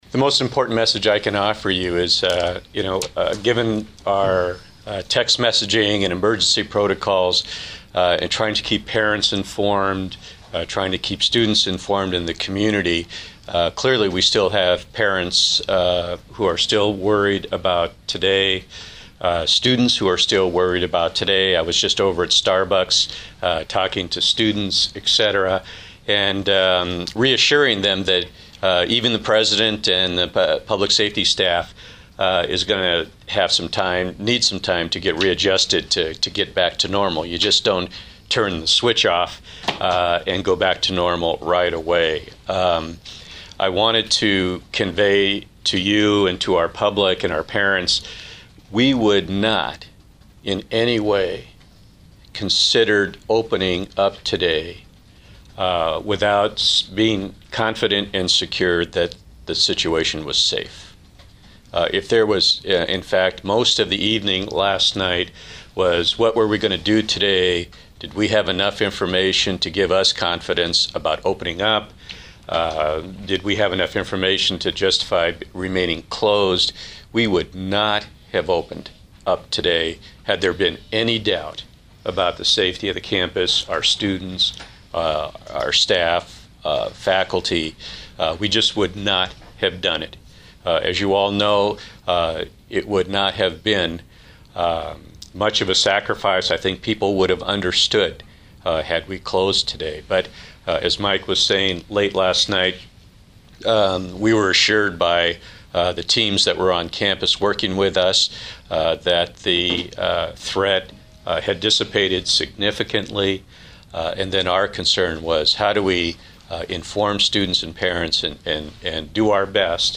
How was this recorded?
At a press briefing held on campus this morning